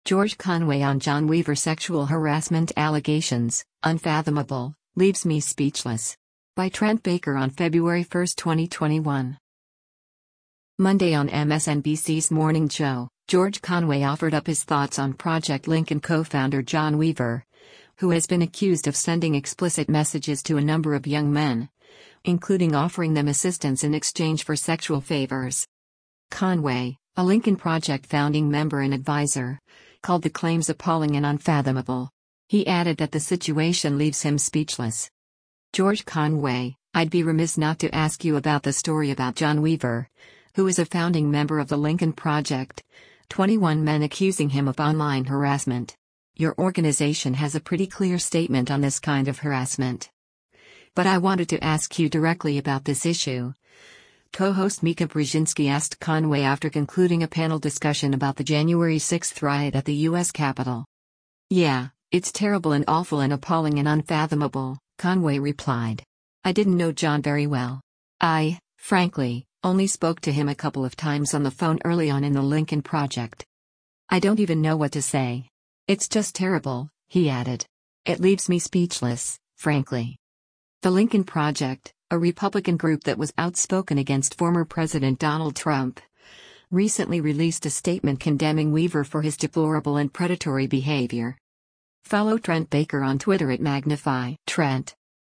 Monday on MSNBC’s “Morning Joe,” George Conway offered up his thoughts on Project Lincoln co-founder John Weaver, who has been accused of sending explicit messages to a number of young men, including offering them assistance in exchange for sexual favors.